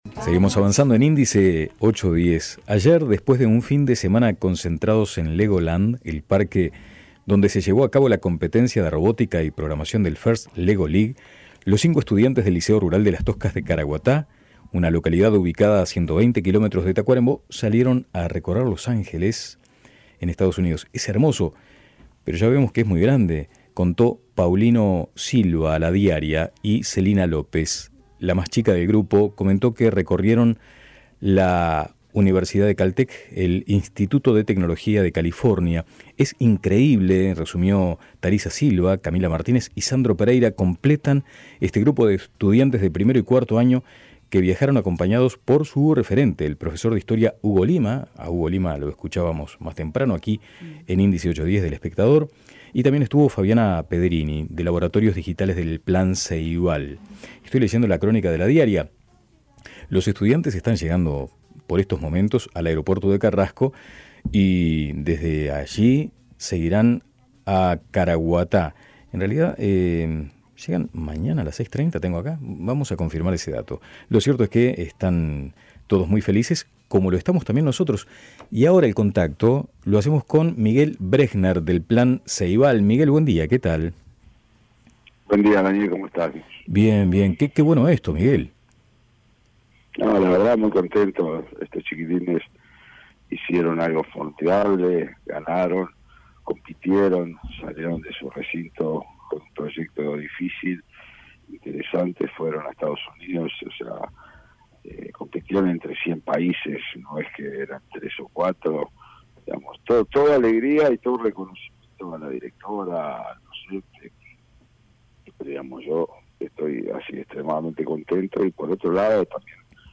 Miguel Brechner, Director del Plan Ceibal, habló en Índice 810 sobre esta experiencia.